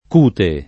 cute [ k 2 te ] s. f.